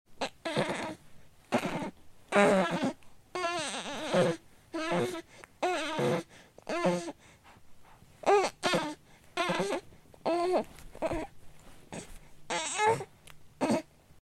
Звуки кряхтения
Кряхтение малыша